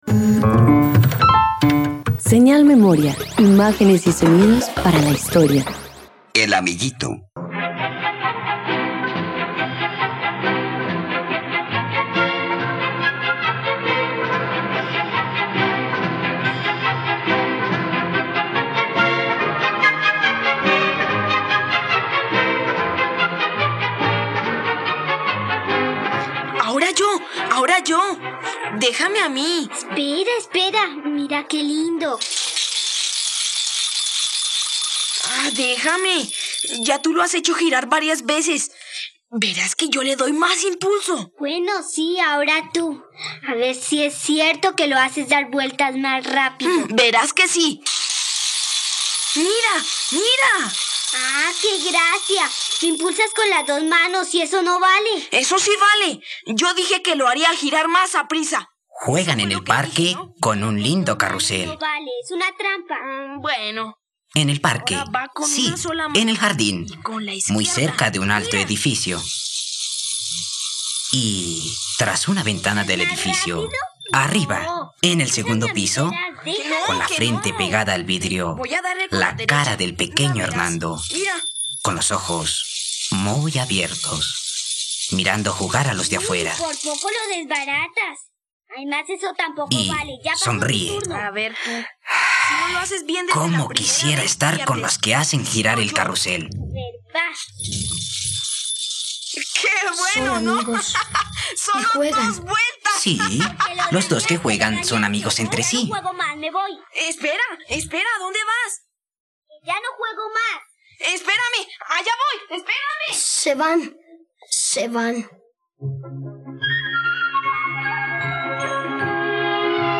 El amiguito - Radioteatro dominical | RTVCPlay